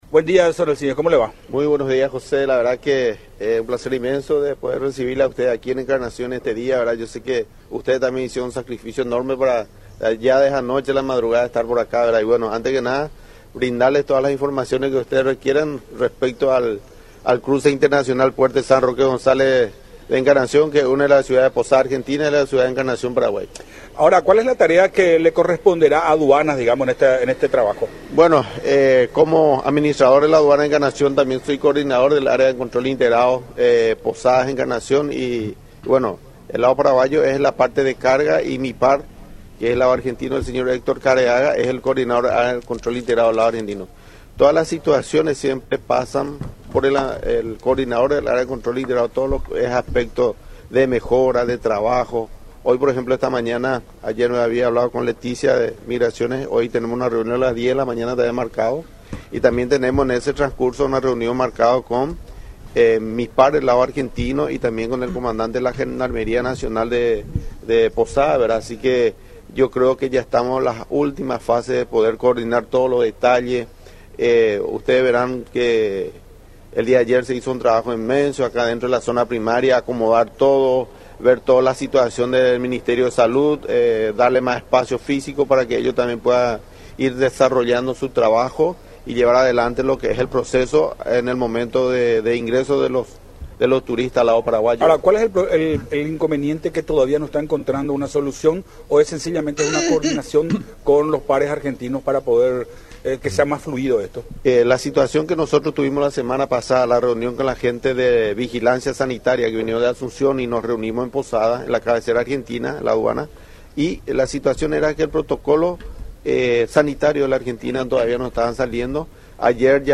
en diálogo con Enfoque 800 por La Unión